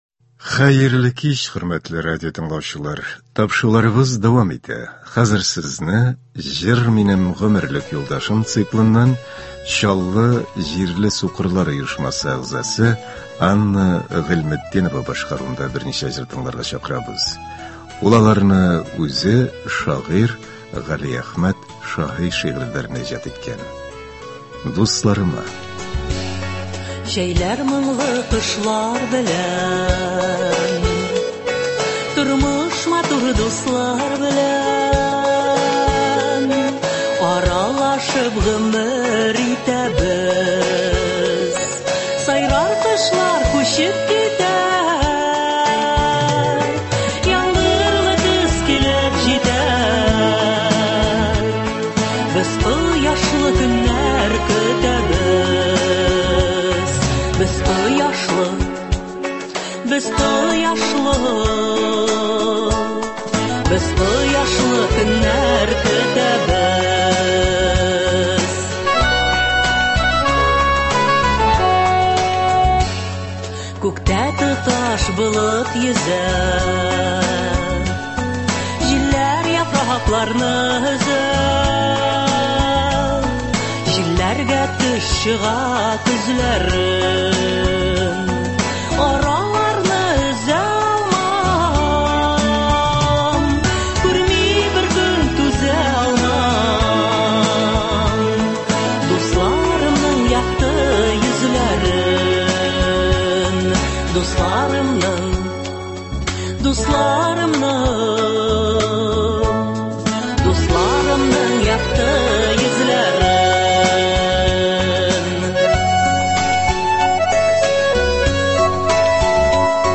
Үзешчән башкаручылар чыгышы.
Концерт (18.03.24)